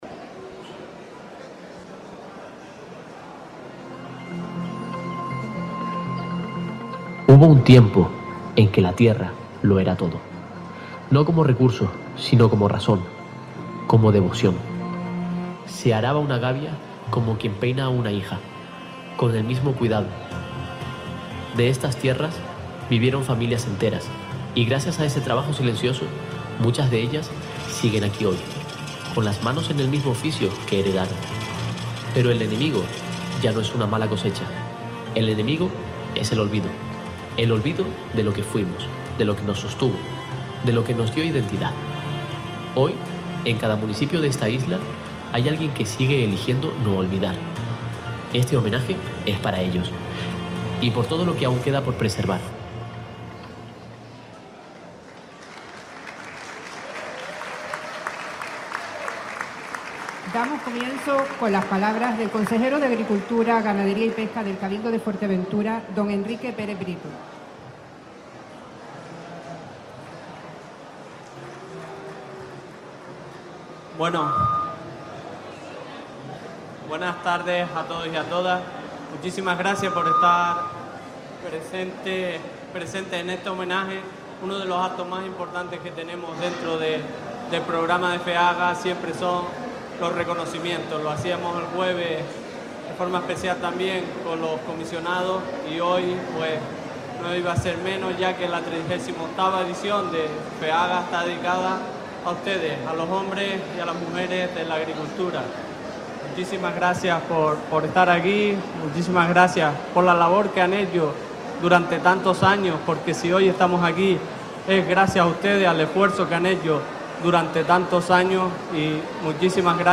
Acto de homenaje del Cabildo de Fuerteventura a los agricultores y agricultoras de la isla - Radio Sintonía
han sido homenajeados en un emotivo acto en el salón institucional de Feaga 2026
Entrevistas